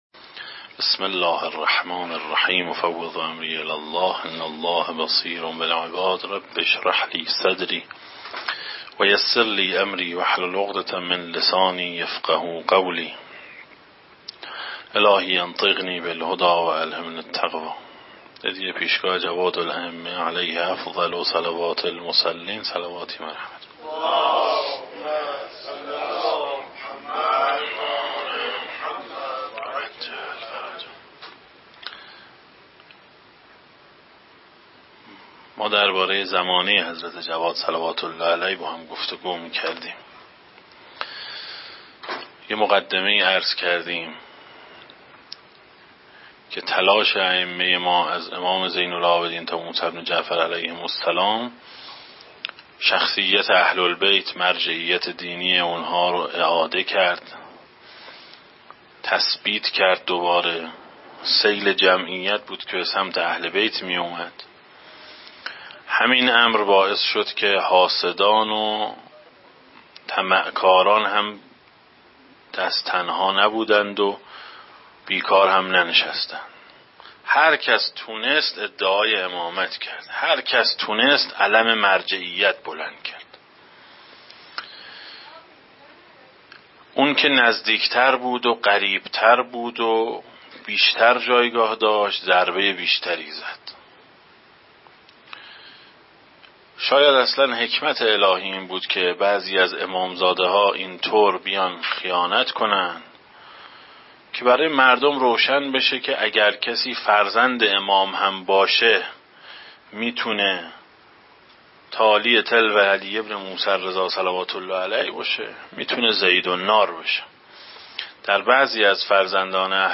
در این بخش از ضیاءالصالحین، صوت جلسه سوم سخنرانی